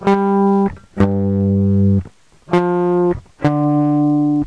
Le guitariste électrique - Exercice d'oreille 2
Solution : Sol, Sol, Fa et Ré